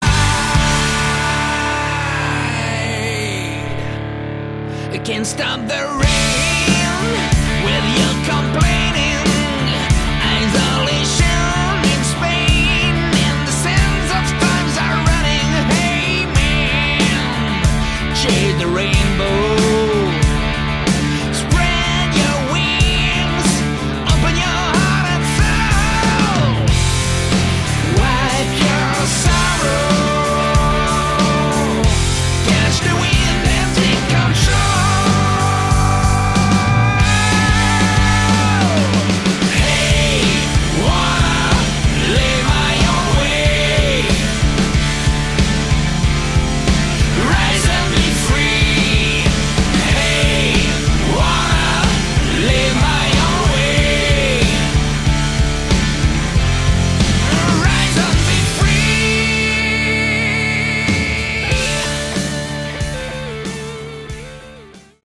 Category: Hard Rock
Lead Guitars
Rhythm Guitars
Drums
Bass
Lead Vocals, Harmonica